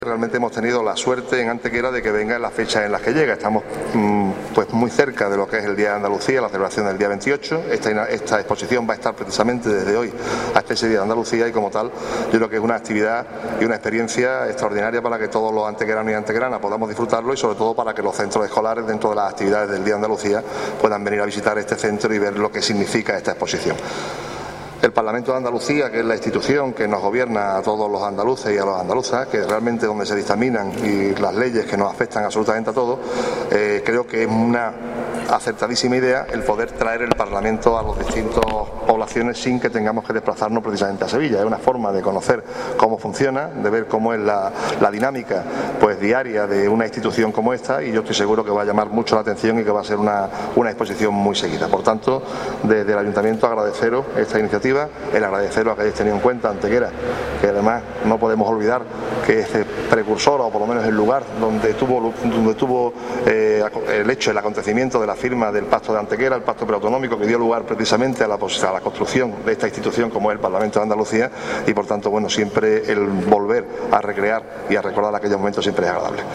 Cortes de voz
Audio: alcalde   1508.98 kb  Formato:  mp3